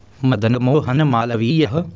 शृणु) /ˈmədənəmhənəmɑːləvɪjəhə/) (हिन्दी: मदनमोहन मालवीय, आङ्ग्ल: Madan Mohan Malviya) इत्याख्यः महापुरुषः अस्मिन् देशे जातः ।